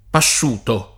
pašš2to] part. pass. di pascere e agg. — raro latinismo pasto [p#Sto] nell’uso poet. ant.: Poi c’ ha pasciuti la cicogna i figli, E come quel ch’è pasto la rimira [p0i k a ppašš2ti la ©ik1n’n’a i f&l’l’i, e kk1me kkUel k H pp#Sto la rim&ra] (Dante) — sim. i cogn. Pasciuta, Pasciuti, Pasciuto